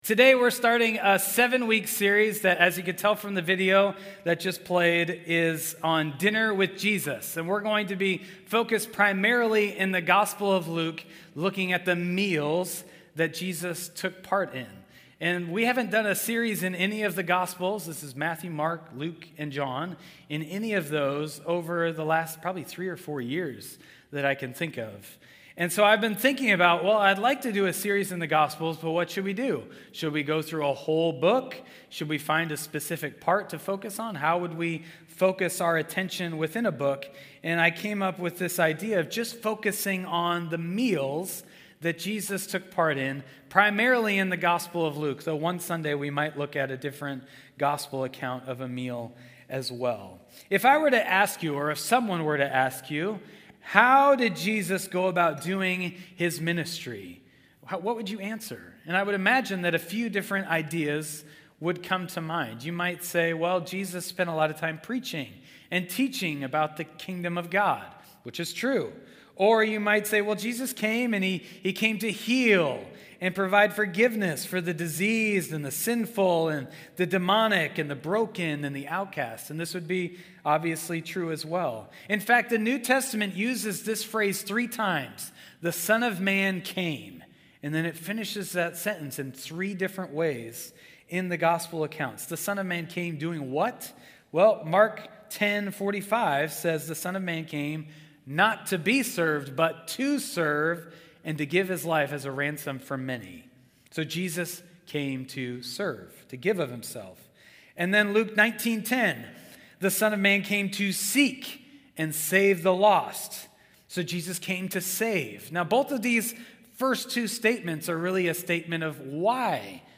New Harvest Church, Salem, Oregon
Sermons